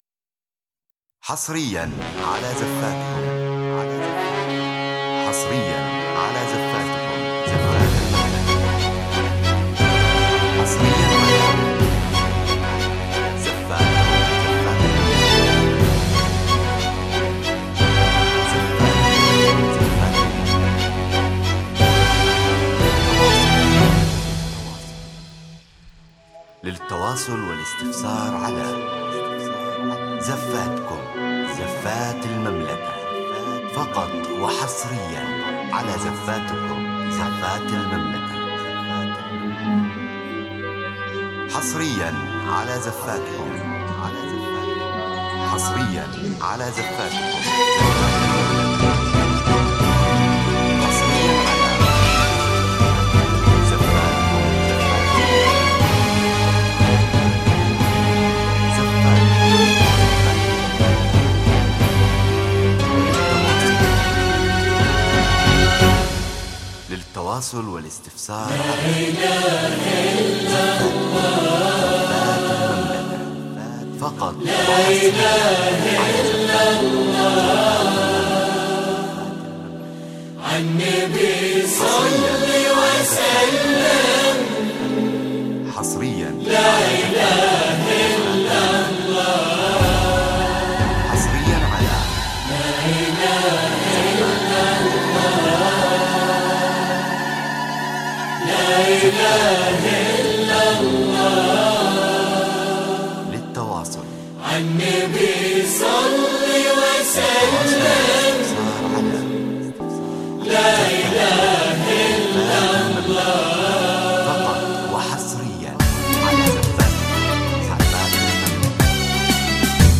مثالية لدخول العروس بأداء خليجي فخم وإحساس راقٍ.